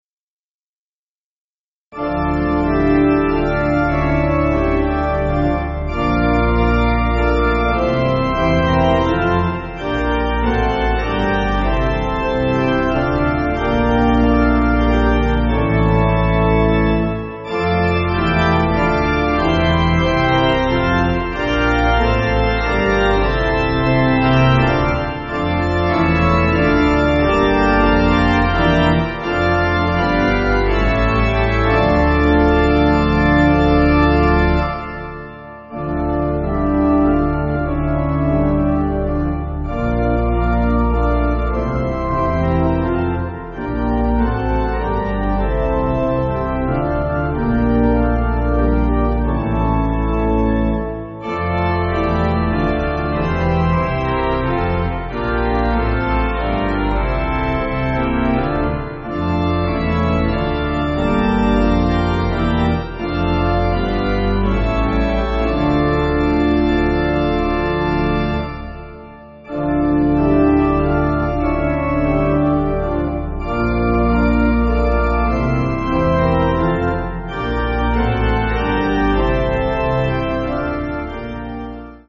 Organ
(CM)   4/Dm